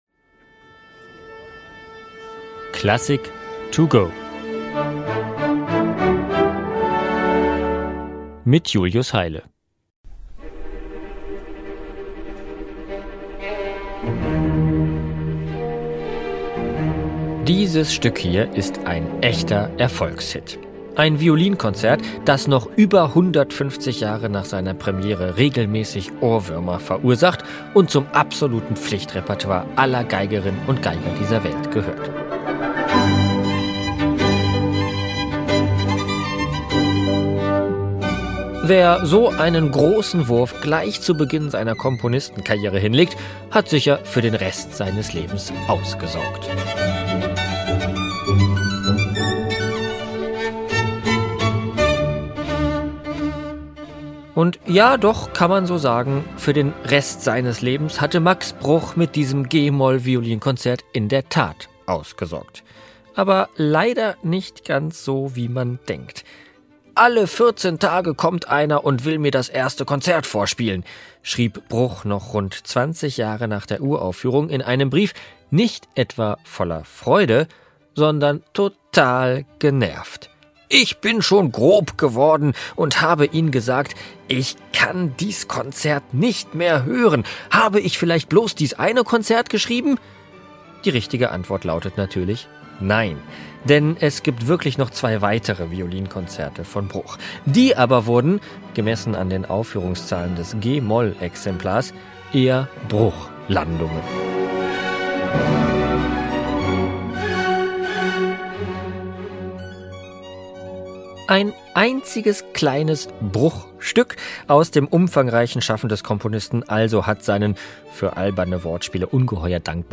kurzen Werkeinführung